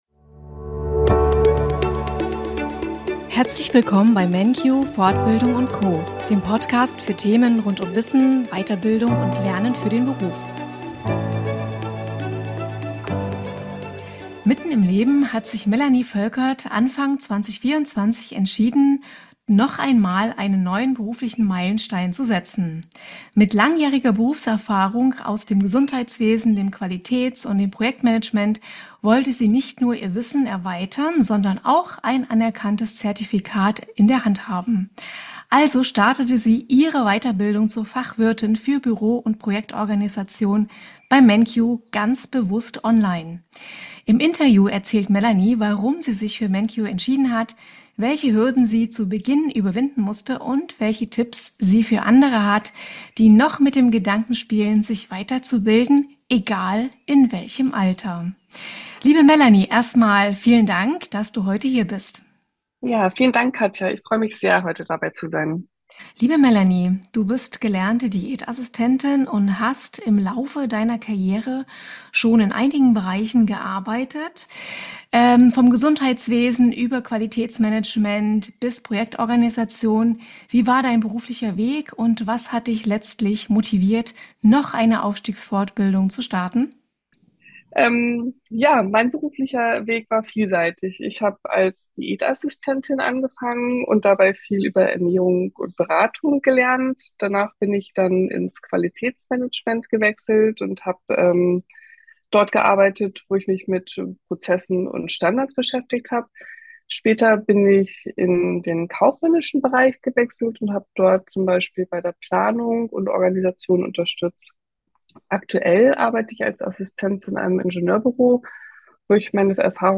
Warum sie sich dafür entschieden hat und welche Erfahrungen sie dabei gemacht hat, erfahrt Ihr im heutigen Interview.